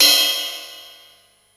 DX Cymbal 02.wav